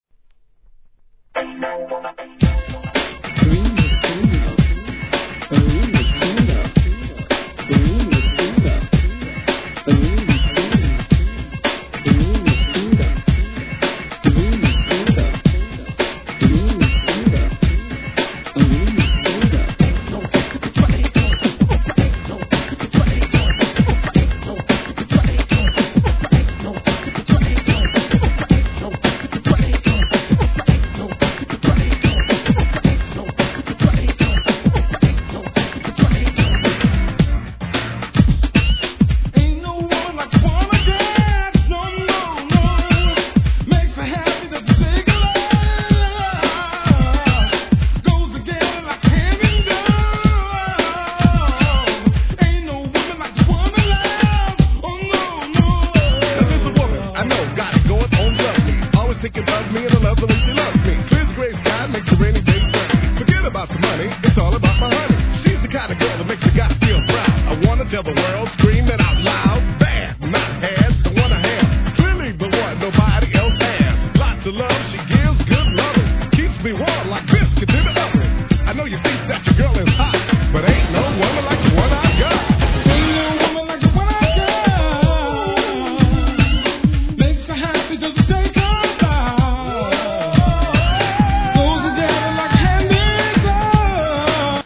HIP HOP/R&B
ソウルフルなトラックに、バックコーラスが見事にはまった1990年作品!!